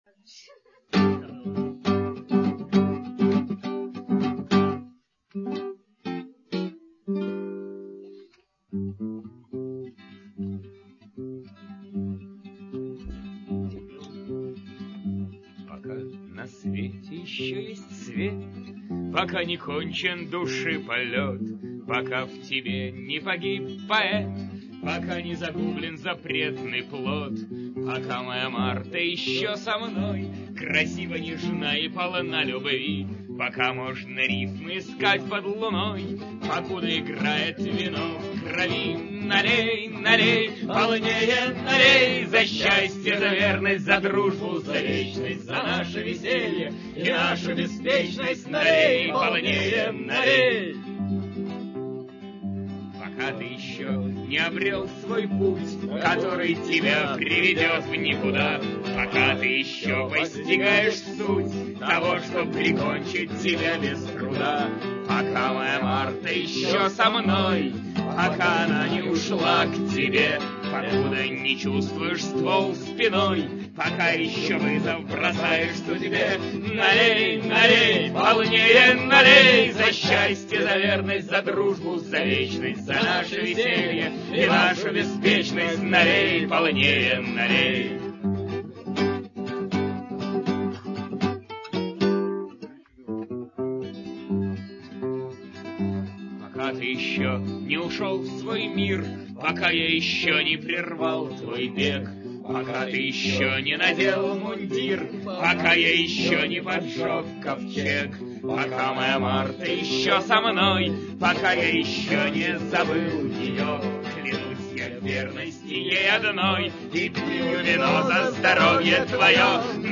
Сцена